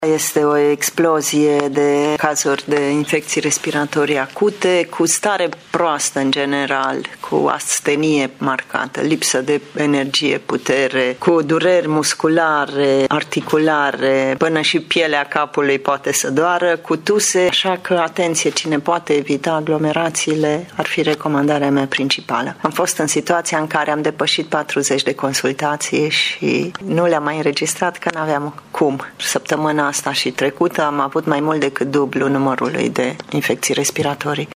medic de familie din Tîrgu-Mureș